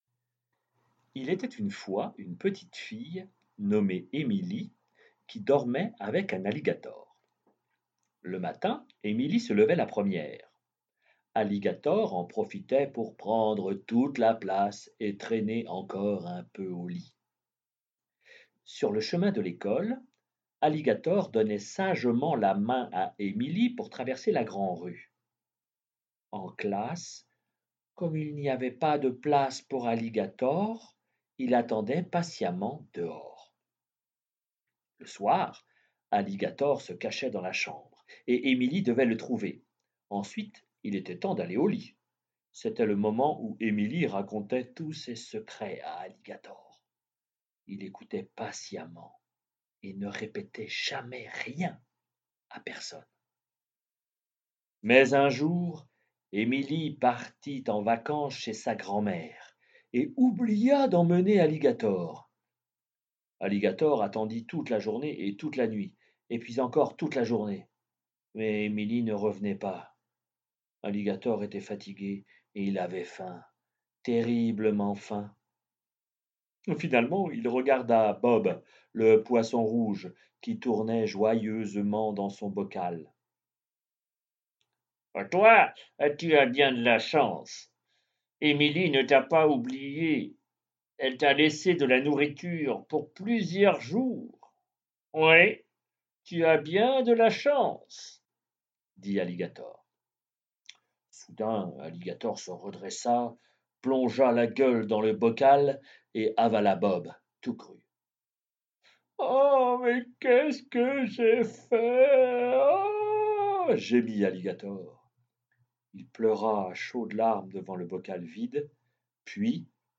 emily et Alligator-texte lu et enregistré
Suite aux consignes données aux parents, voici le texte lu et enregistré de Emily et Alligator.